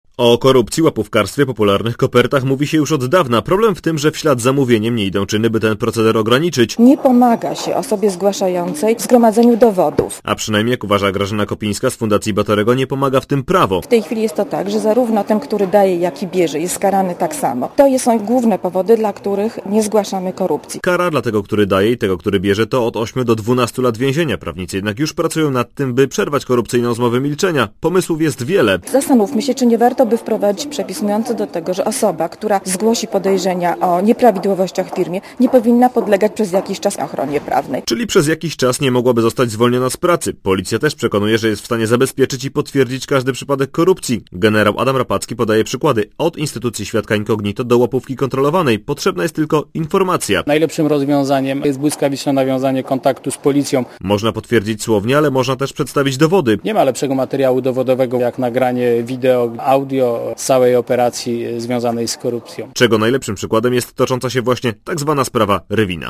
Posłuchaj relacji korespondenta Radia Zet (0,4 MB)